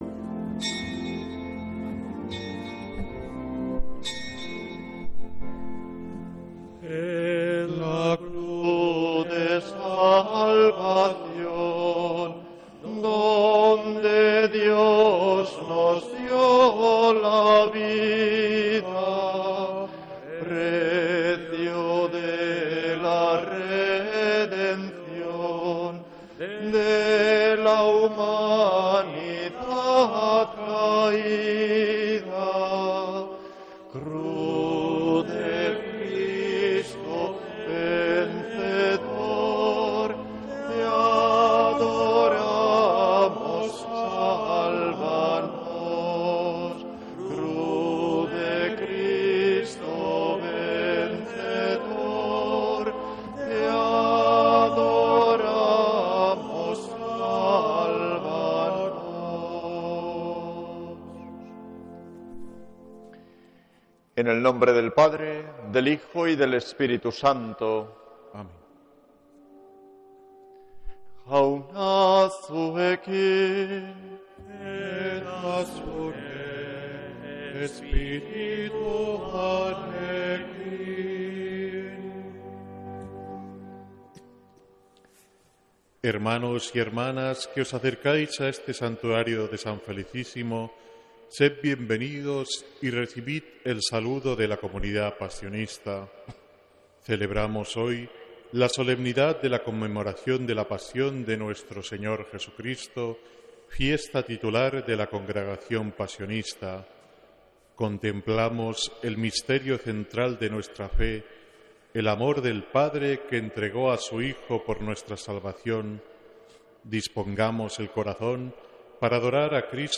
Santa Misa desde San Felicísimo en Deusto, domingo 15 de febrero de 2026